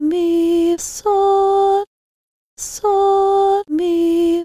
Solfege Minor Third: Mi-So
A minor third ascending and descending in solfège: Mi-So, So-Mi
mi-so-minor-third.mp3